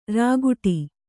♪ rākuḍi